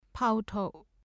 ဖဦးထုပ် [pʰâ-ʔouʔtʰouʔ ]子音字「ဖ」の名前。